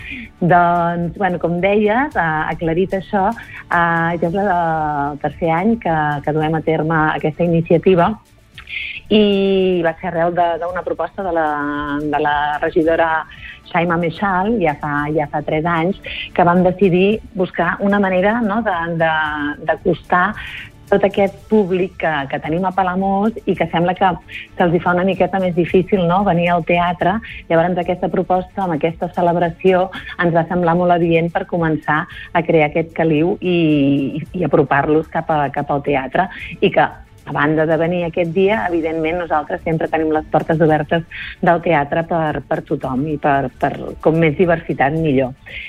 Supermatí - entrevistes
I per parlar dels principals espectacles i propostes que hi podem trobar ens ha visitat al Supermatí la regidora de cultura de l’Ajuntament de Palamós, Núria Botellé.